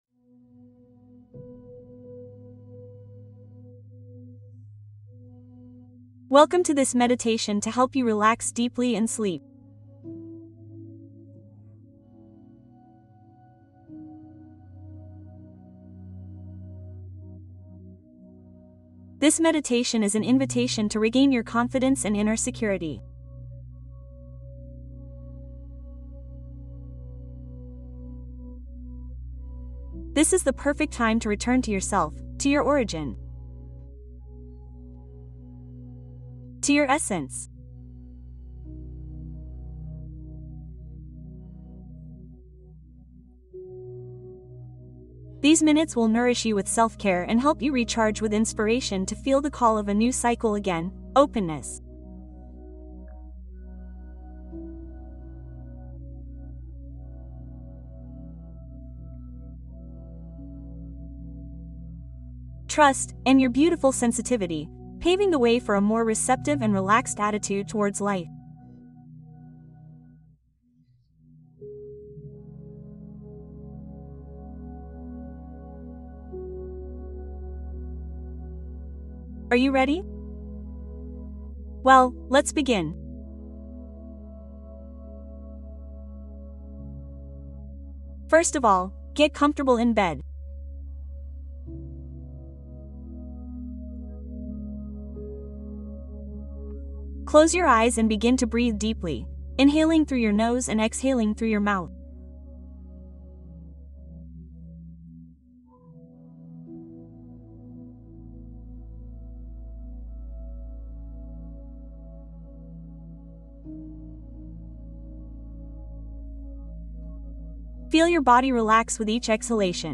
Sanación profunda Meditación para dormir y relajar cuerpo, mente y espíritu